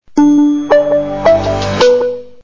power on.mp3